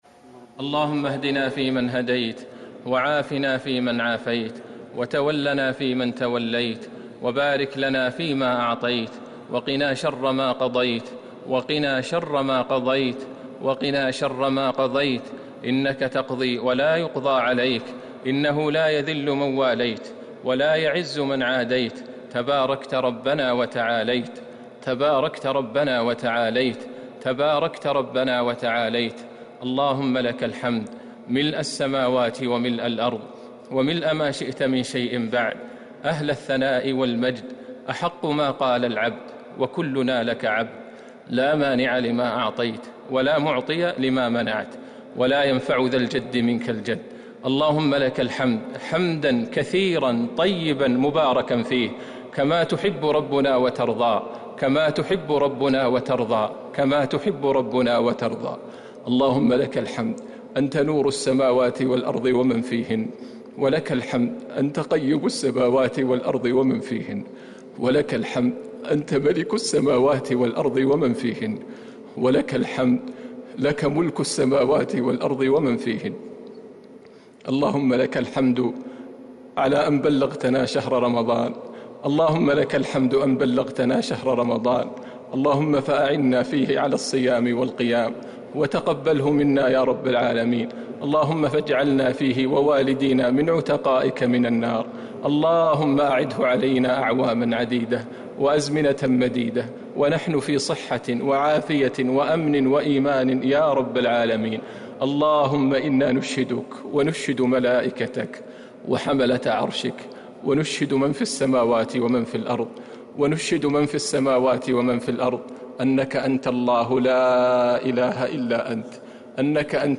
دعاء القنوت ليلة 2 رمضان 1441هـ > تراويح الحرم النبوي عام 1441 🕌 > التراويح - تلاوات الحرمين